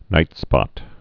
(nītspŏt)